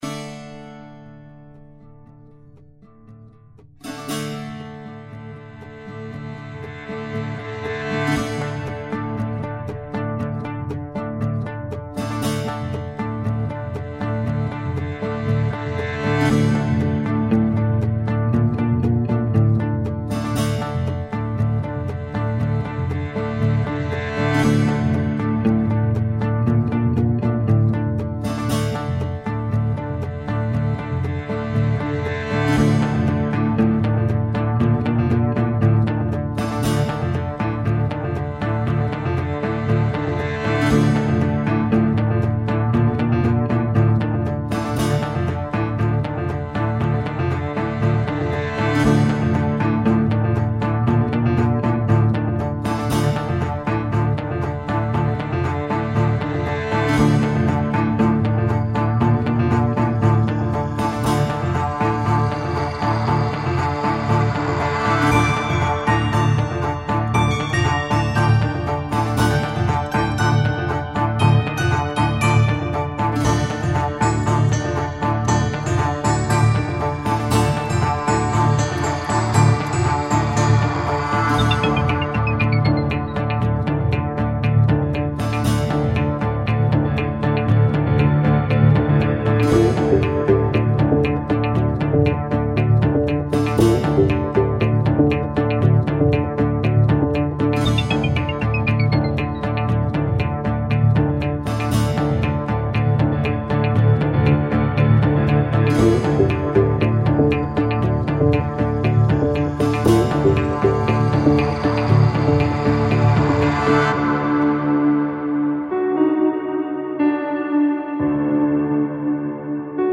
其中一些乐器非常古老，您可能是第一次听到它们。